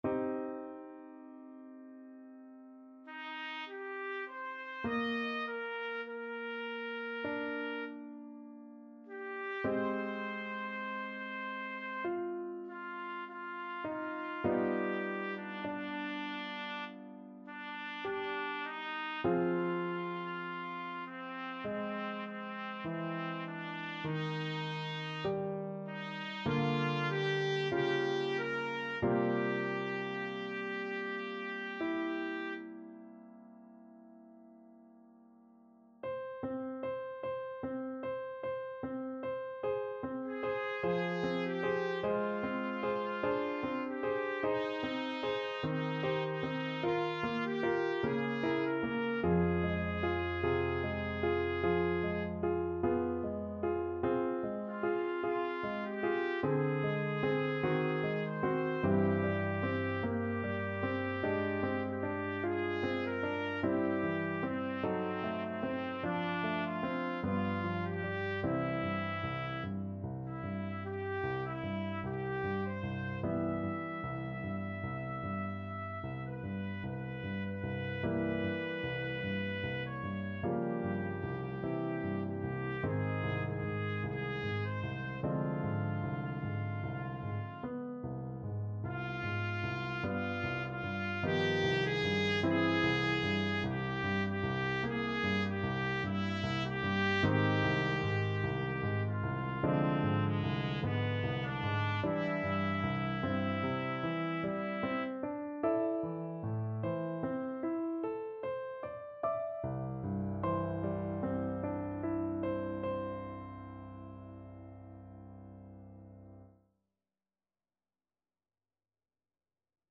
Trumpet version
~ = 100 Lento =50
4/4 (View more 4/4 Music)
Classical (View more Classical Trumpet Music)